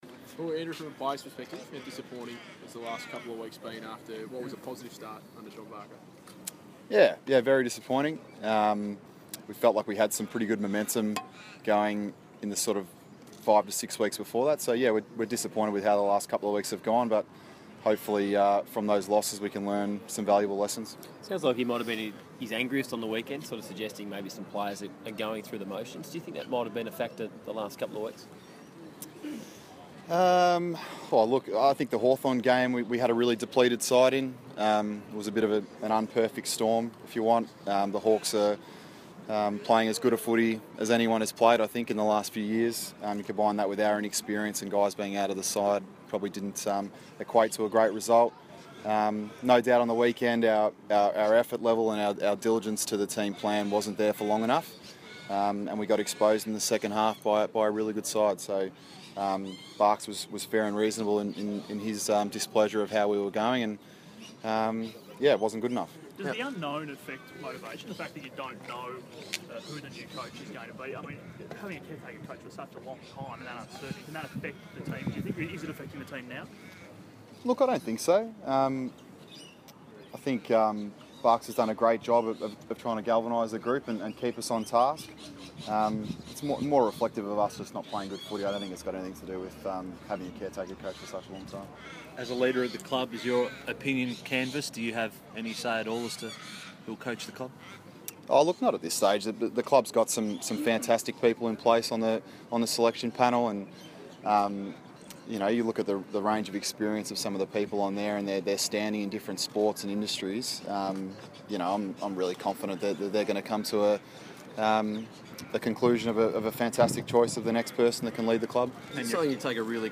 Blues veteran Andrew Carrazzo, talking to the press from Lygon Street's Piazza Italia, discusses Carlton's loss to the Kangaroos and his own playing future.